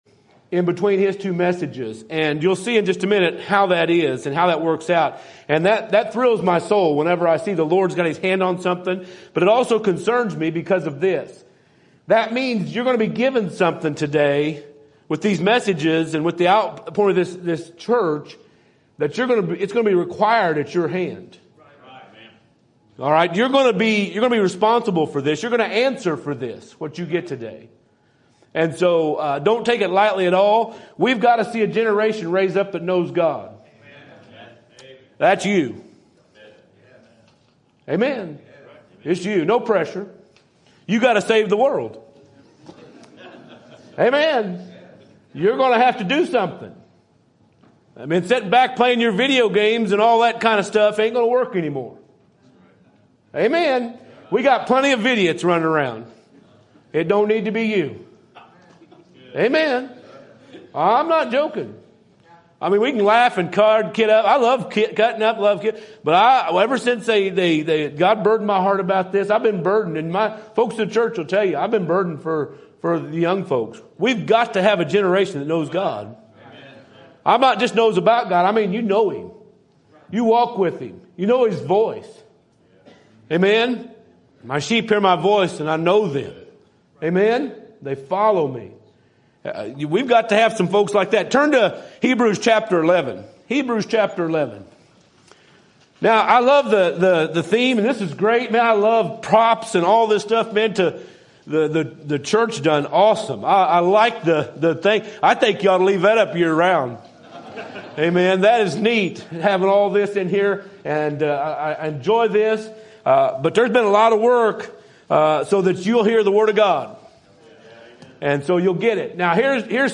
Sermon Topic: Youth Conference Sermon Type: Special Sermon Audio: Sermon download: Download (17.76 MB) Sermon Tags: Anchor Rope Faith World